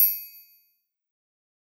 SouthSide Trap Bell (3).wav